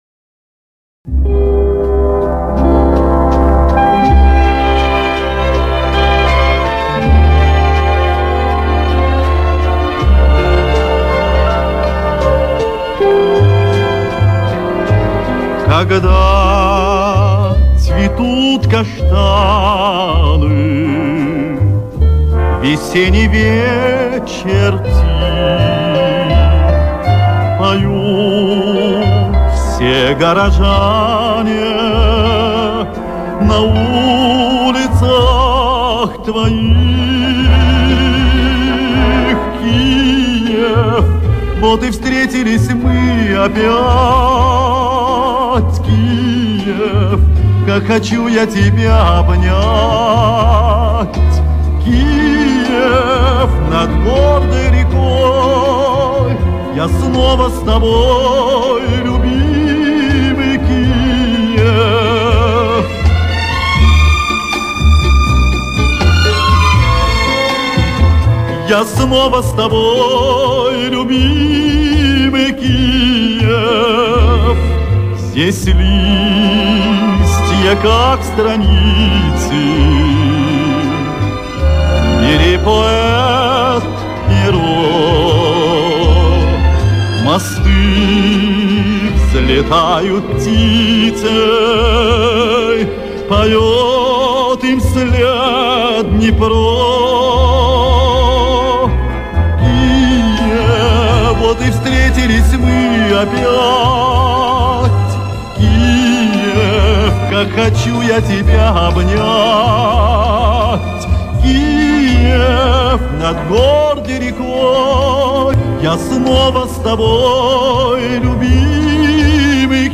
Душевная песня о столице УССР